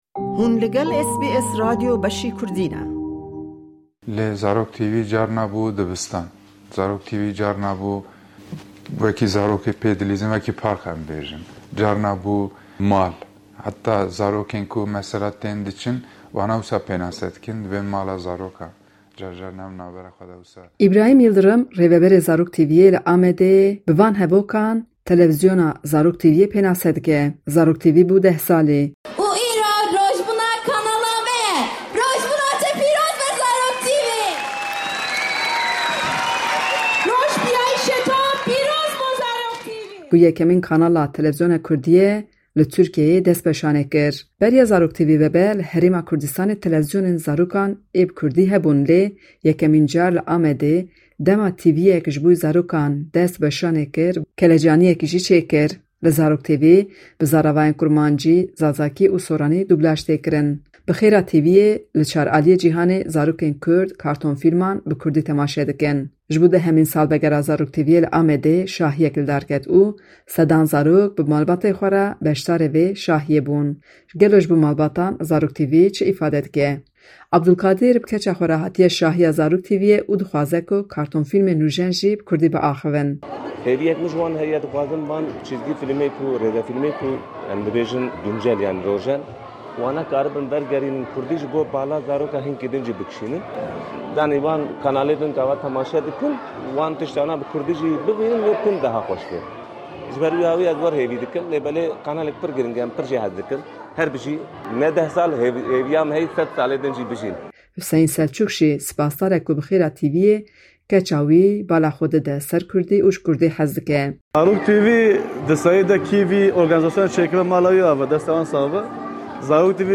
raporteke taybet